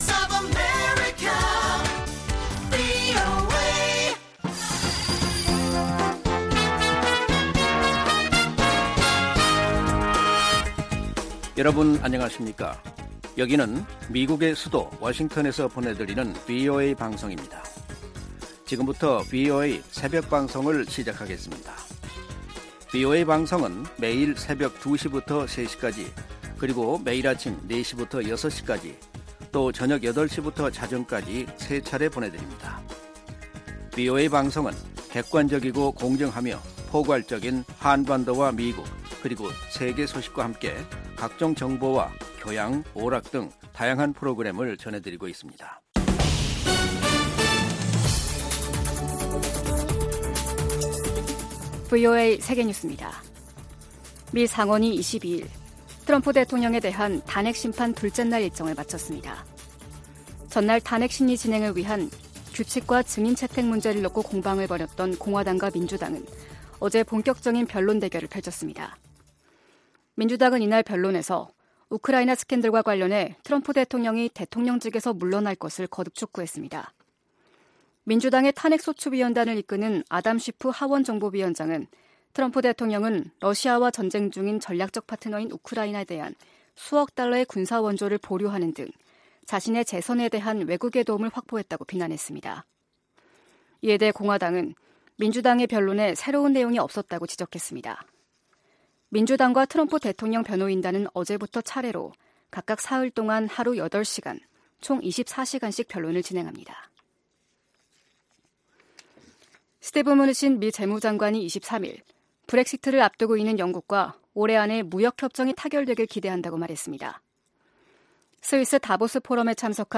VOA 한국어 '출발 뉴스 쇼', 2020년 1월 24일 방송입니다. 미 국무부 고위 관리는 대북 외교가 느린 속도로 진행되고 있다며, 북한에 대한 압박을 지속할 것이라고 밝혔습니다. 미 하원 군사위 민주당 측은 오는 28일로 예정된 ‘한반도 안보 현황 점검’ 청문회에서 북한의 위협과 미군 대비태세, 미-한 방위비 분담금 협상 현황을 집중적으로 다룰 것이라고 예고했습니다.